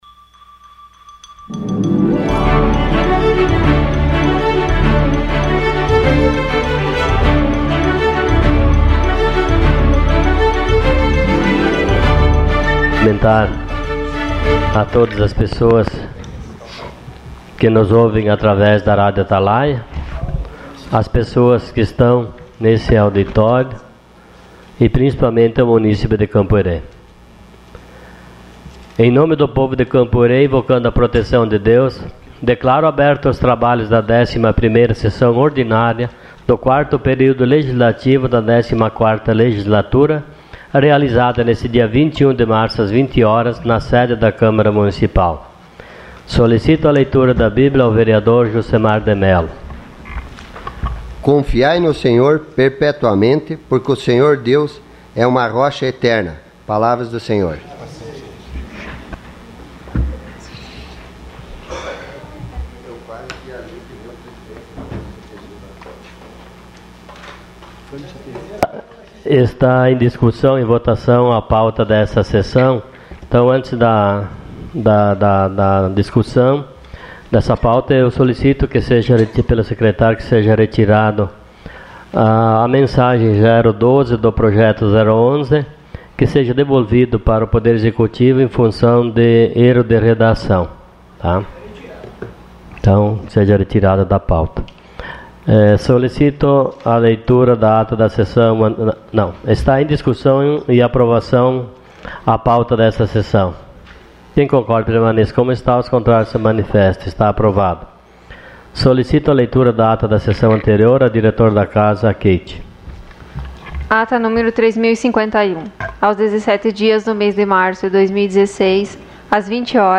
Sessão Ordinária dia 21 de março de 2016.